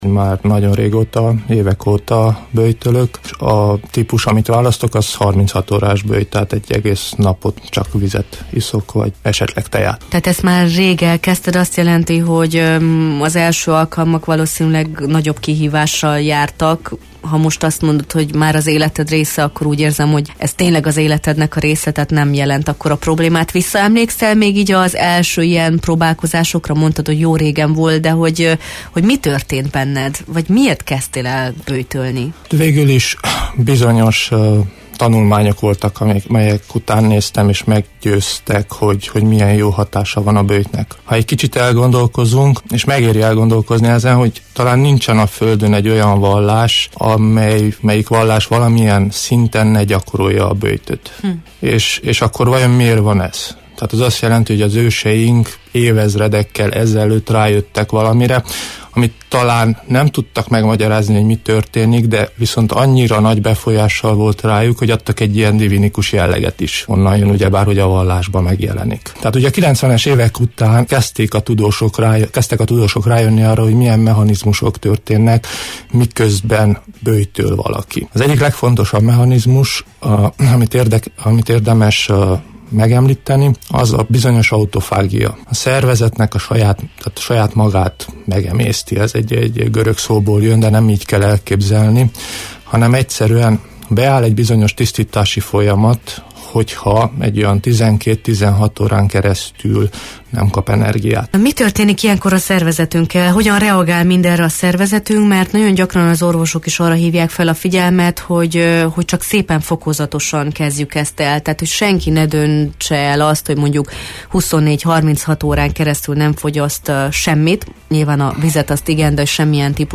Böjtölés, de hogyan? - táplálkozási szakértőt kérdeztünk - Marosvasarhelyi Radio